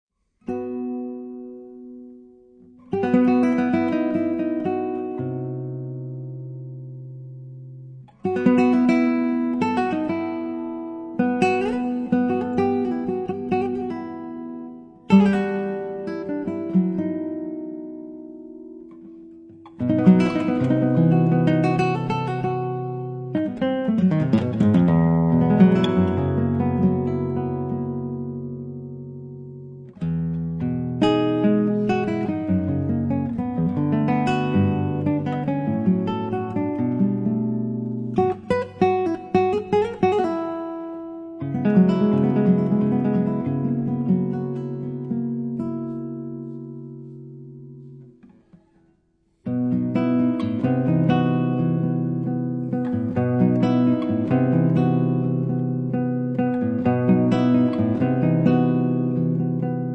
Chitarra Acustica e elettrica
sassofoni
contrabbasso